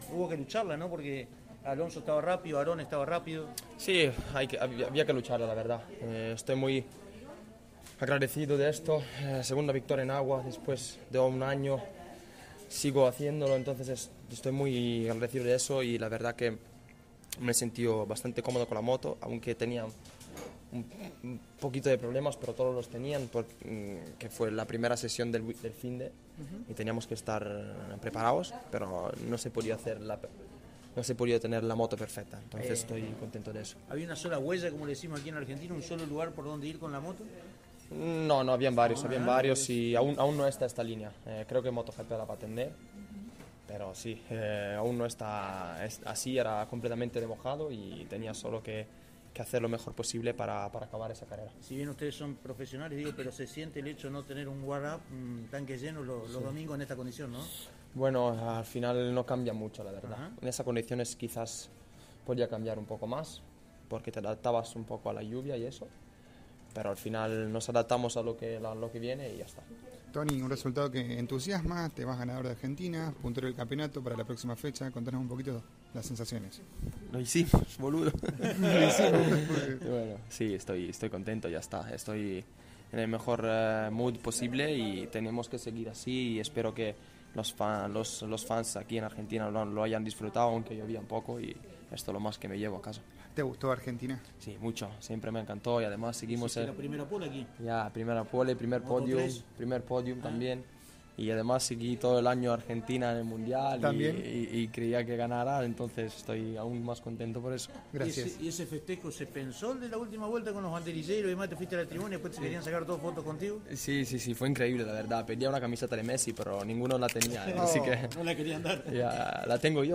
Luego de la conclusión de cada una de las carreras de las divisionales pertinentes, nuestros micrófonos pudieron captar los testimonios de cada uno de los vencedores, los integrantes de cada podio y los representantes más importantes de la máxima: MotoGP.
A continuación, cada una de las entrevistas:
Tony Arbolino (ganador)